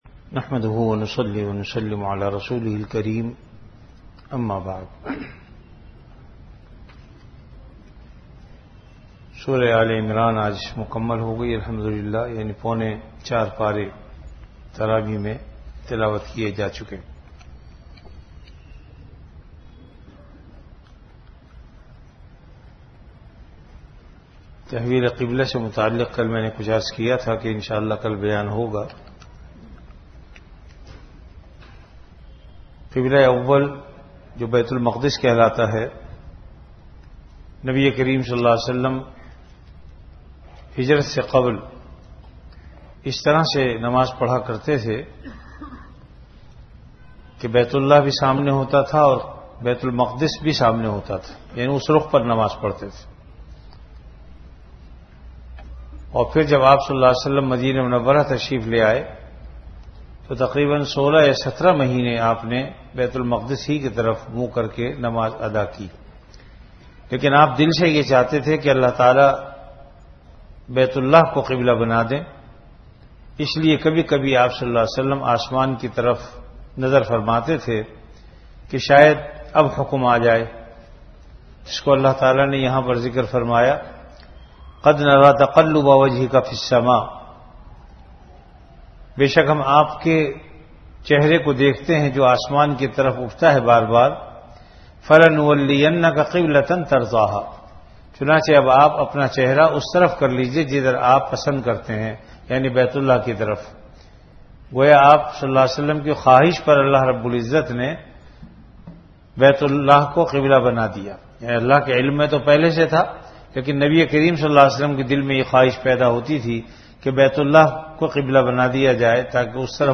Ramadan - Taraweeh Bayan · Jamia Masjid Bait-ul-Mukkaram, Karachi
3-Taraweeh Bayan.MP3